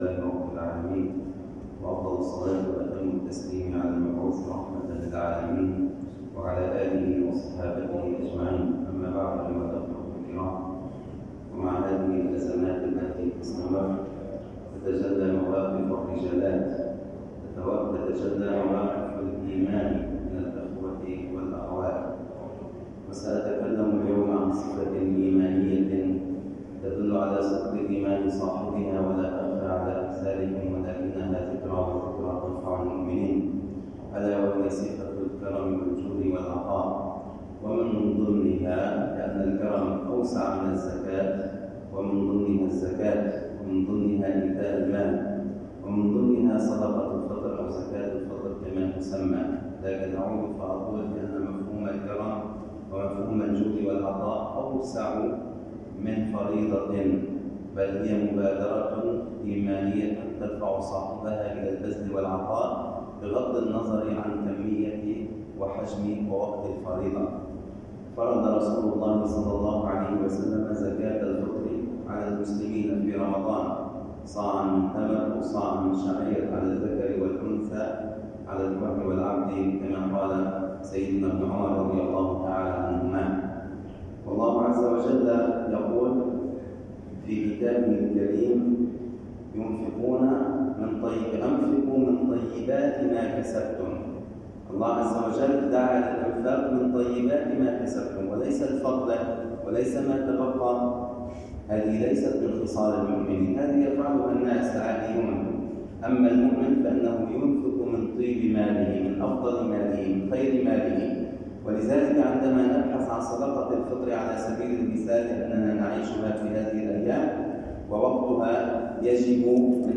[منبر الجمعة]